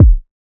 [Kick] Portland.wav